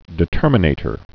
(dĭ-tûrmə-nātər)